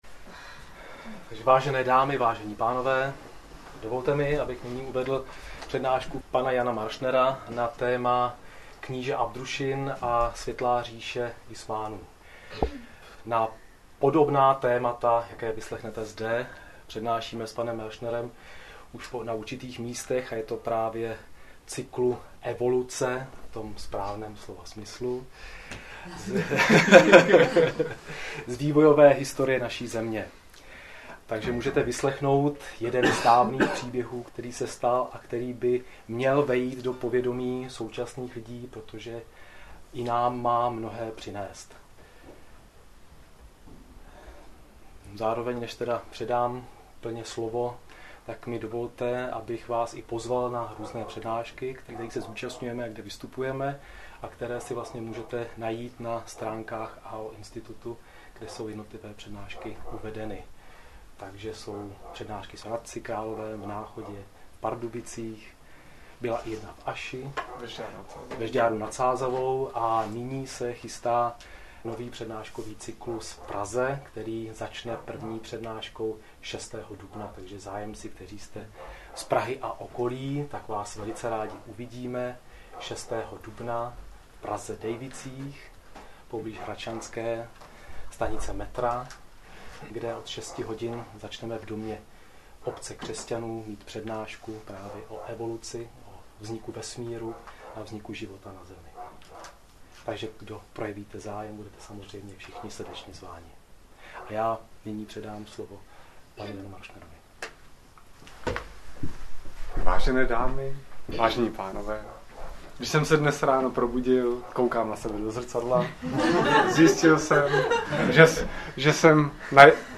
Audio přednášky